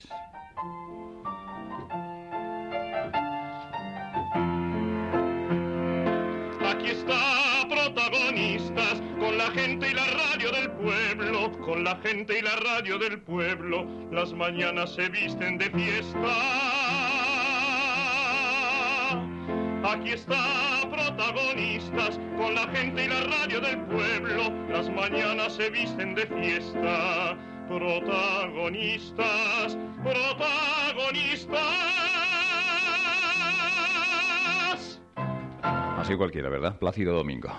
cantada pel tenor